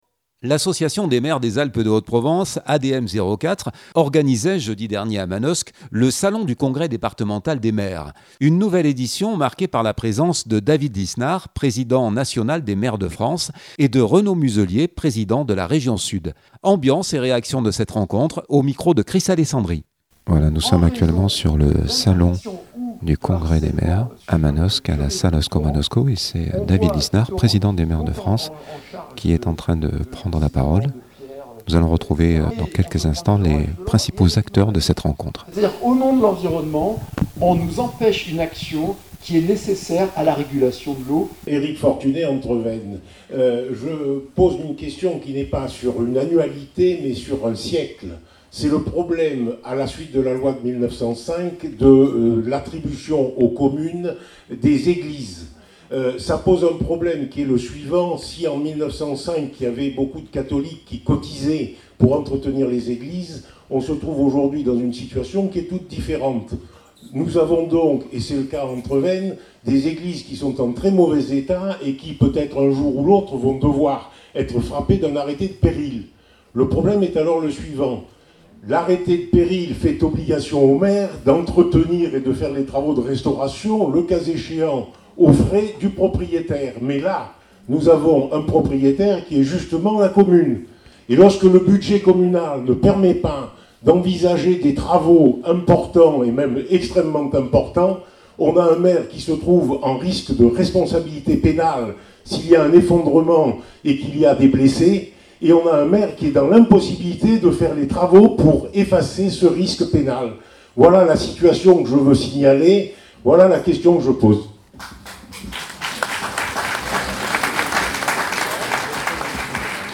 Ambiance et réactions de cette rencontre